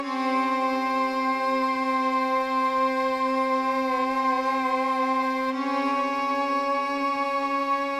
阿拉伯语的节奏
Tag: 阿拉伯语 节奏 阿拉伯